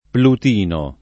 Plutino [ plut & no ]